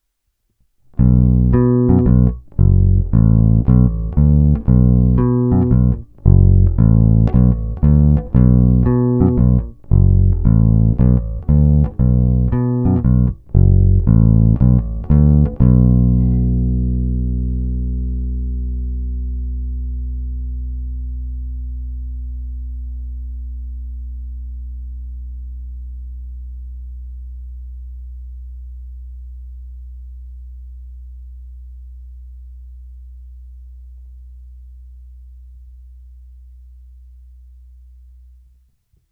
Zvuk je poctivý Precision, ten z nejpoctivějších.
Není-li uvedeno jinak, jsou provedeny rovnou do zvukové karty a jen normalizovány, s plně otevřenou tónovou clonou.
Hra nad snímačem